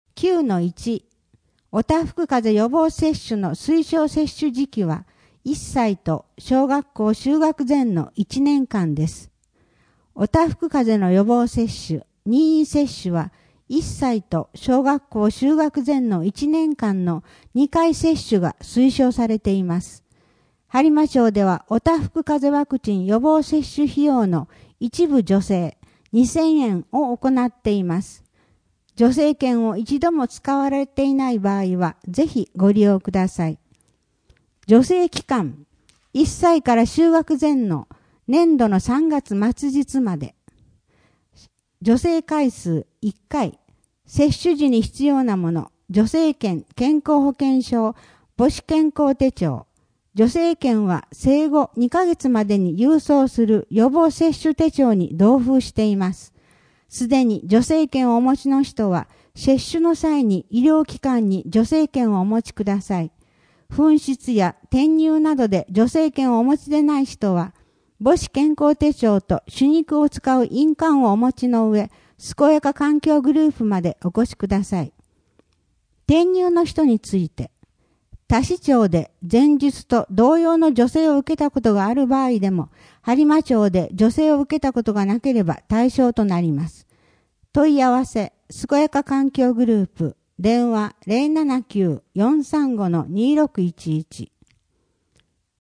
声の「広報はりま」3月号
声の「広報はりま」はボランティアグループ「のぎく」のご協力により作成されています。